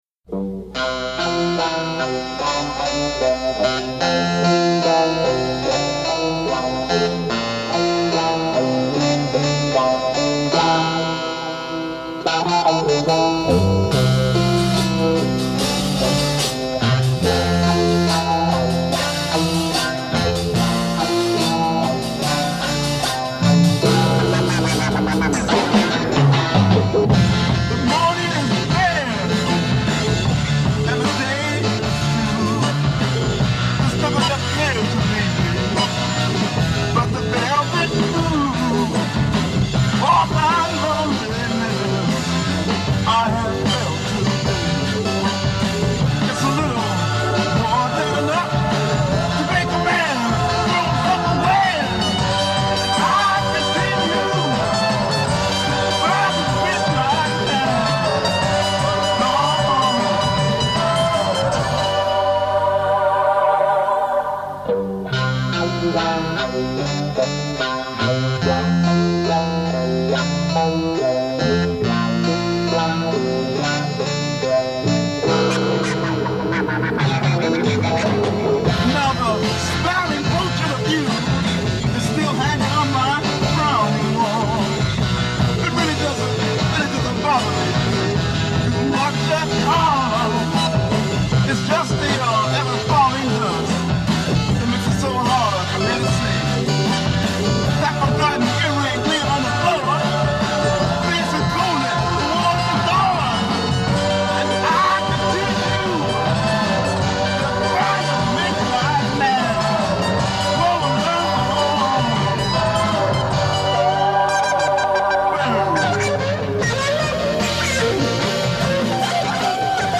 guitar and vocal
bass guitar
drums) with unidentified chorus and harpsichord.
A "Lamp" theme 8 Guitar (wah wah) with harpsichord
Repeat and fade. e